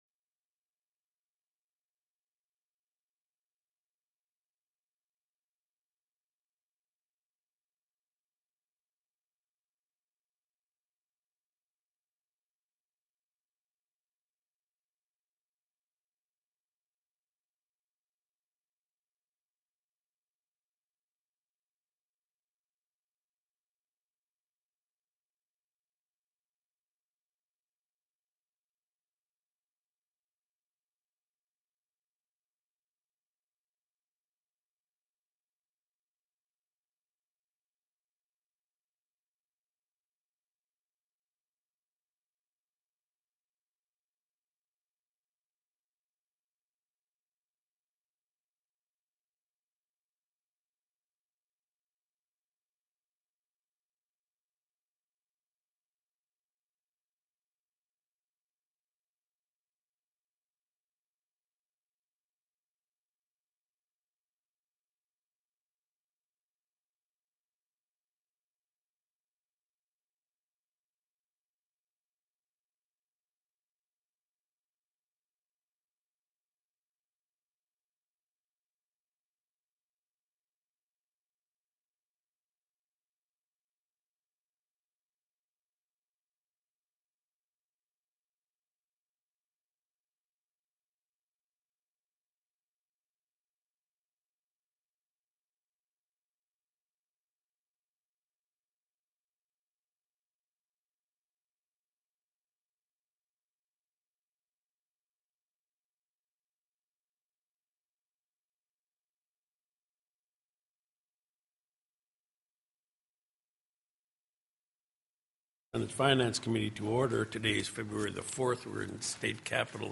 The audio recordings are captured by our records offices as the official record of the meeting and will have more accurate timestamps.
Overview: Governor's FY 2026 Supplemental TELECONFERENCED